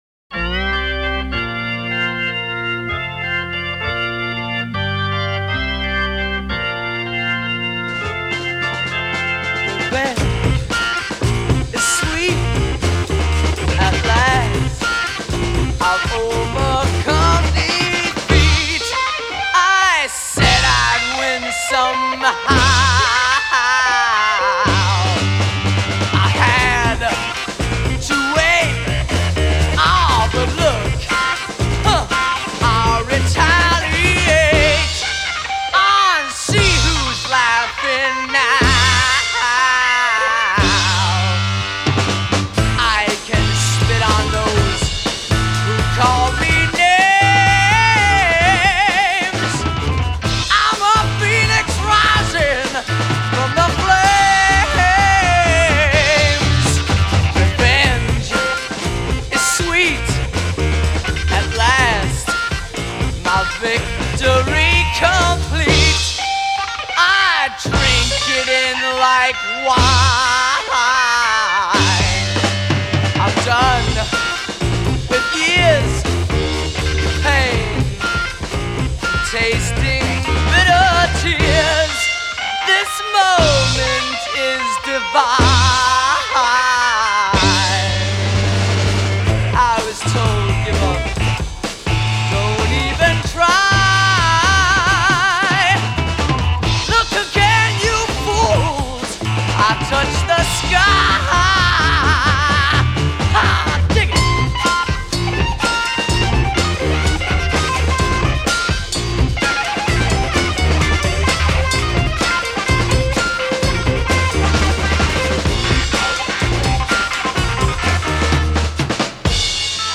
Érdekes felállás volt – egy dobos és egy orgonista.